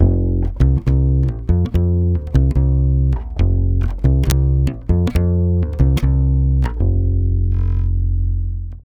140BAS FM7 1.wav